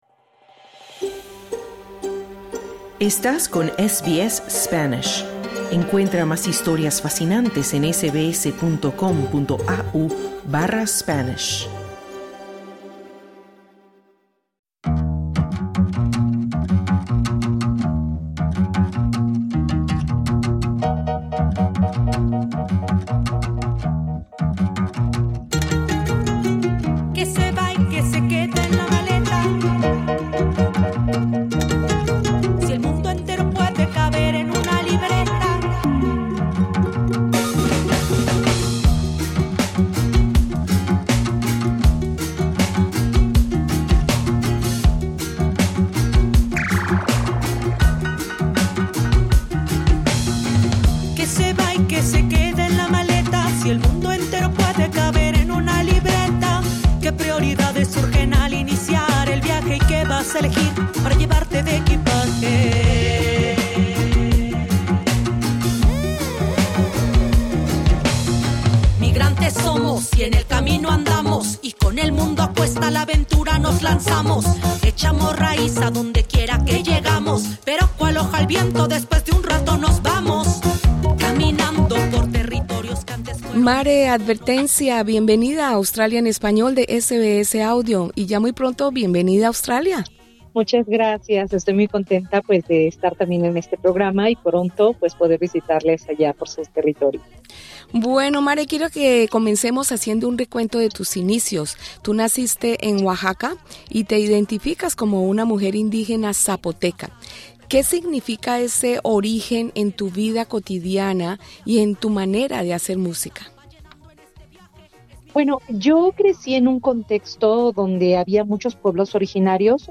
En entrevista con SBS Audio, la artista zapoteca habla sobre identidad, migración y el hip-hop como herramienta política y de memoria.